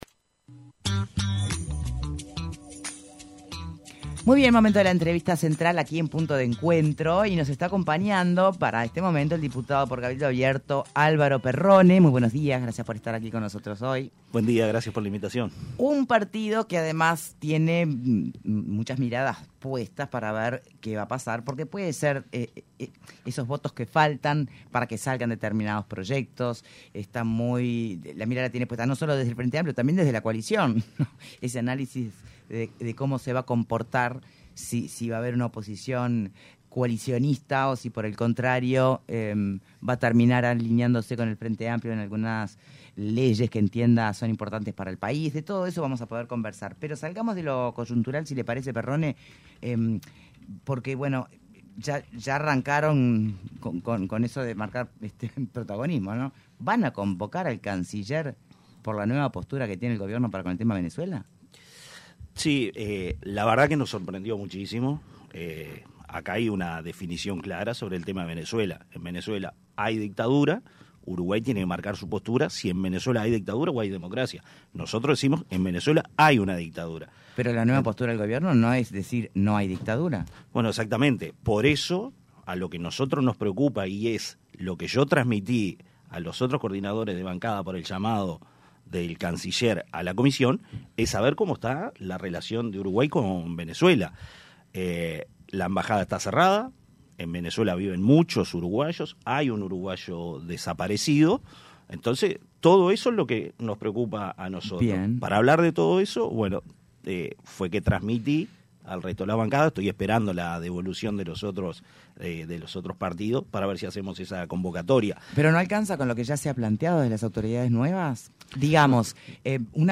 Entrevista a Álvaro Perrone: